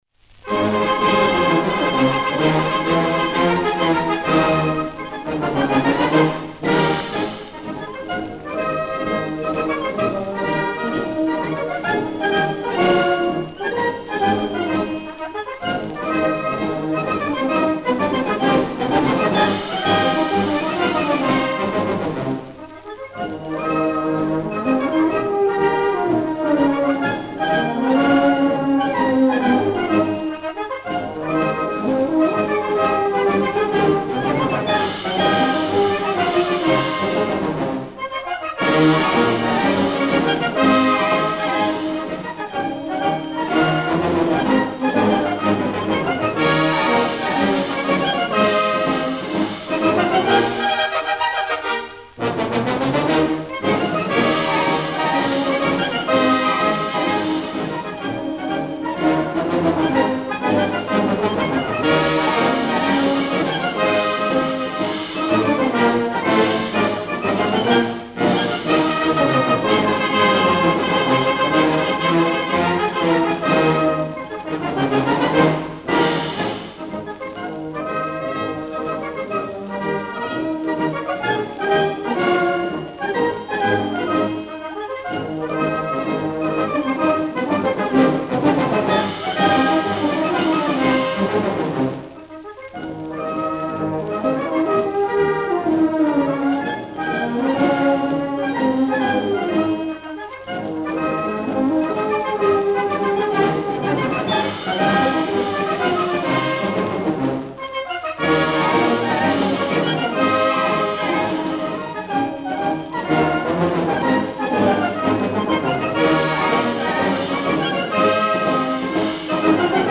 Марши